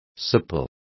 Complete with pronunciation of the translation of suppler.